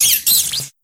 Grito de Togedemaru.ogg
Grito_de_Togedemaru.ogg